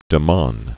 (də män, măɴ), Paul 1919-1983.